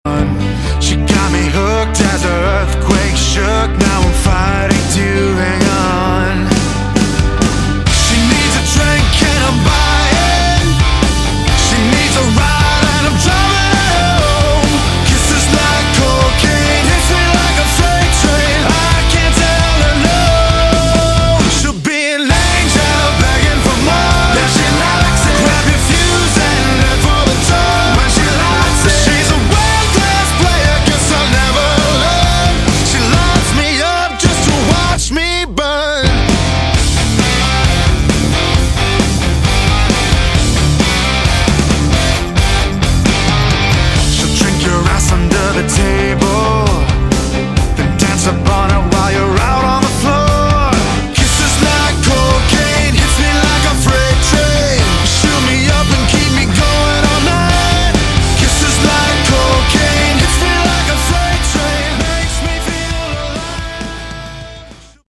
Category: Modern Hard Rock
lead guitar, vocals
drums
bass